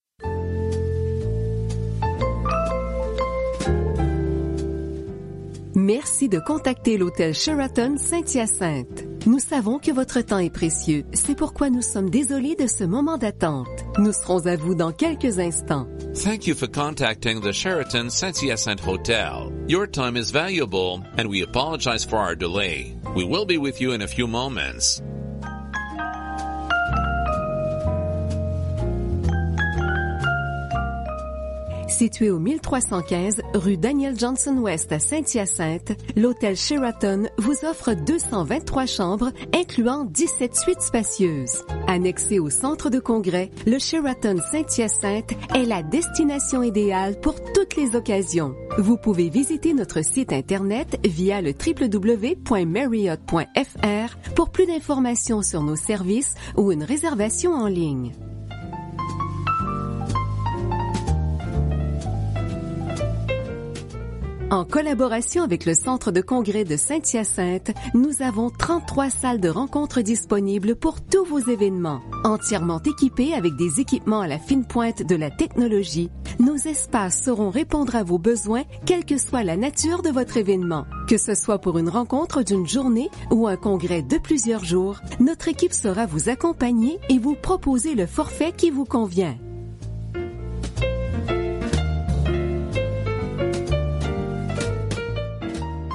Comprend un message d’attente, la rédaction, la narration et le montage musical (pas de contrat et pas de versement mensuel)